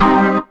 B3 CMIN 1.wav